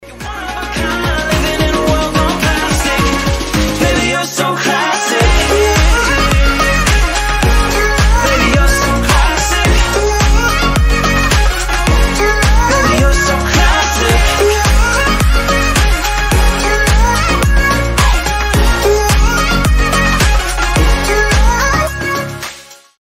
мужской голос
Dance Pop
tropical house
теплые